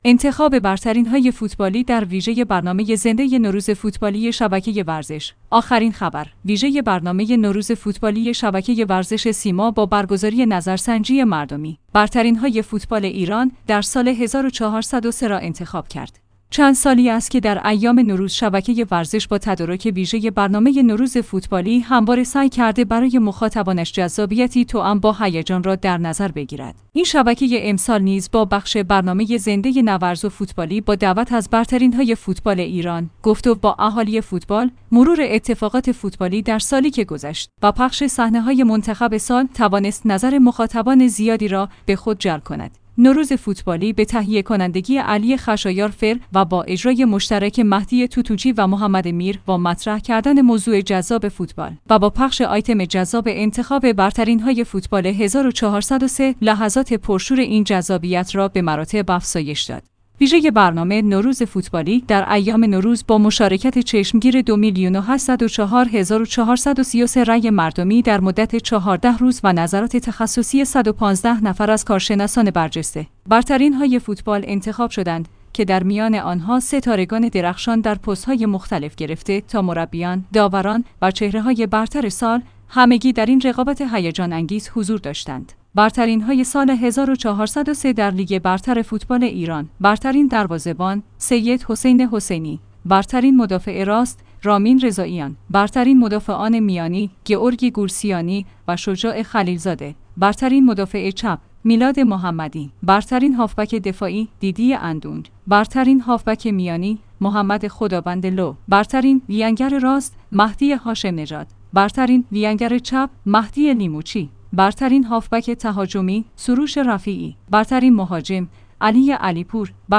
انتخاب برترین‌های فوتبالی در ویژه برنامه زنده نوروز فوتبالی شبکه ورزش